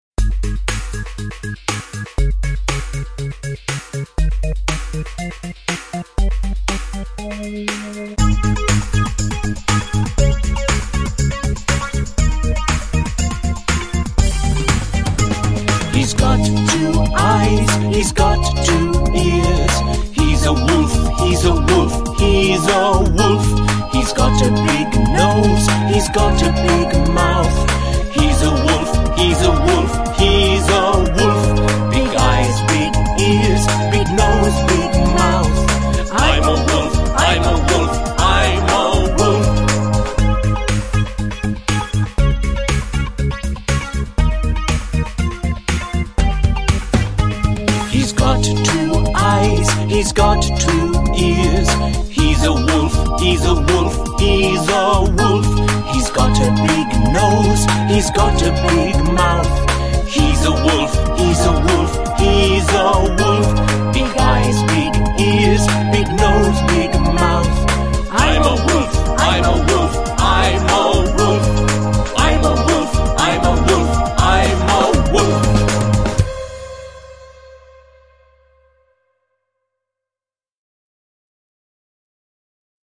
Песенка для изучения английского языка для маленьких детей.